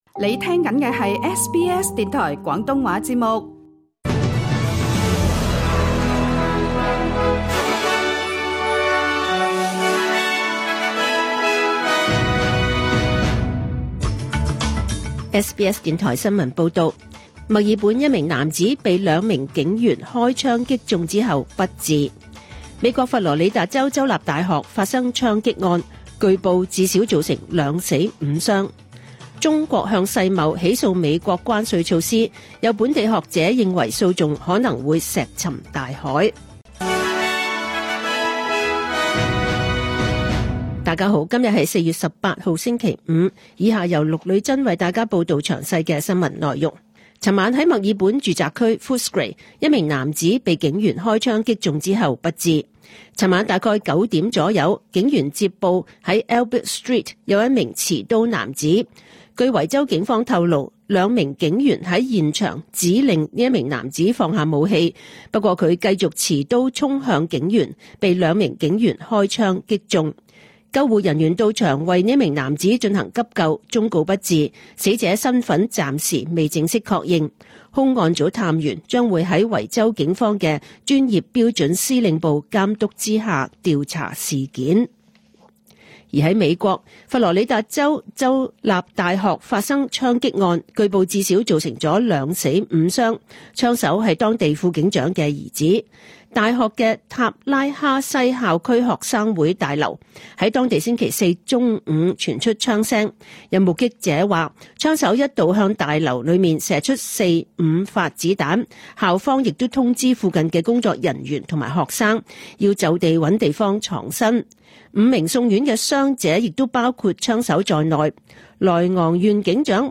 2025 年 4 月 18 日 SBS 廣東話節目詳盡早晨新聞報道。